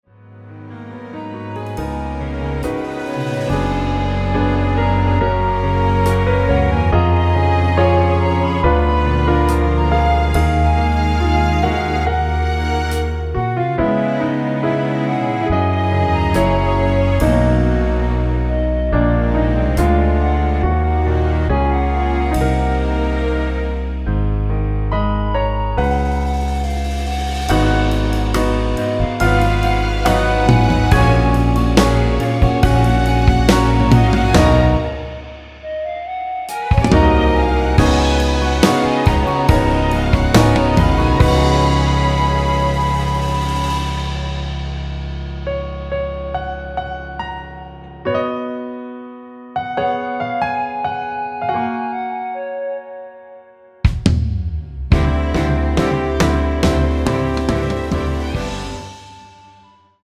원키에서(-1)내린 멜로디 포함된(1절+후렴) 진행되게 편곡한 MR입니다.
앞부분30초, 뒷부분30초씩 편집해서 올려 드리고 있습니다.
중간에 음이 끈어지고 다시 나오는 이유는